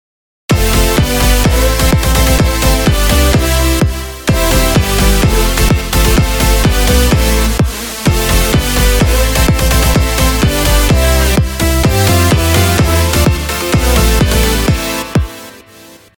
משהו שהתחלתי היום עוד לפני מיקס הרגע סיימתי אני עוד מתכנן להוסיף כרנאה בילד אפ וקטע שני וכו’… בקיצור תשמעו טראק בסגנון ברוקס.mp3 עכשיו נזכרתי שהייתי צריך להחליף לקיק קצת יותר נוכח…